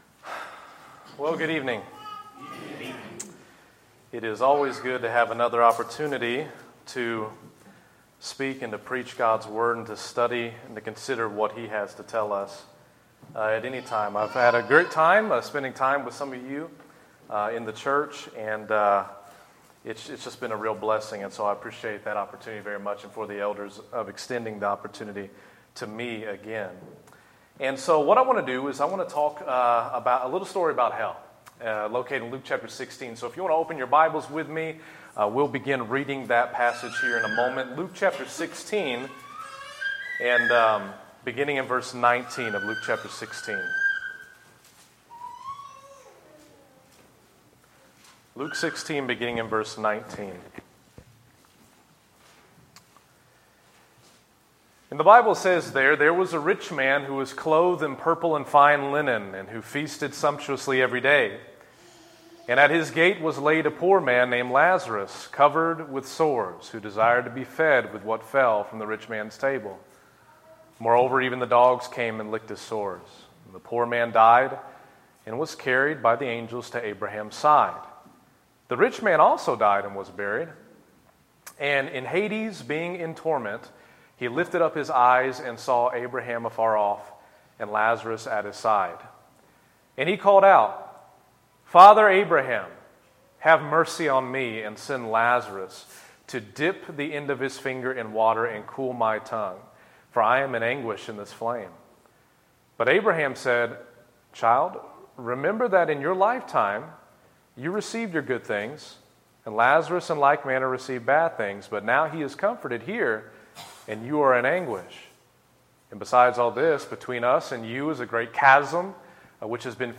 Evening Sermon – A Story About Hell